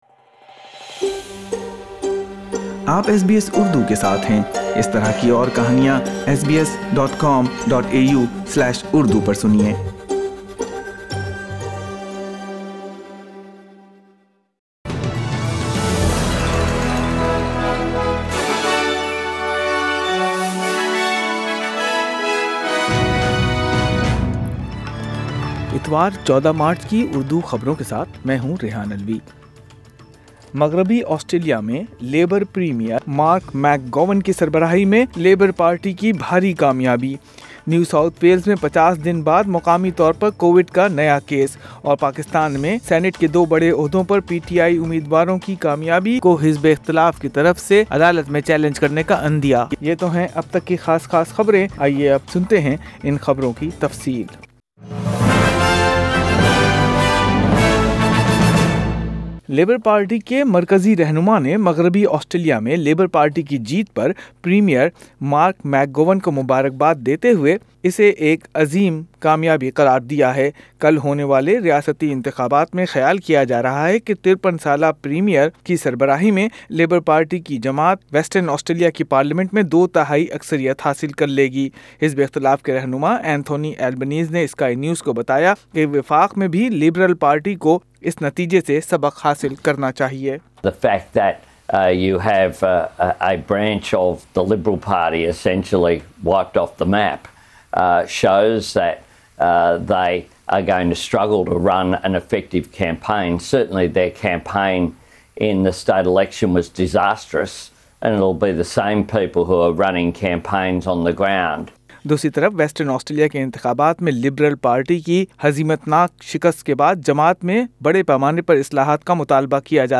Urdu News Sunday 14 March 2021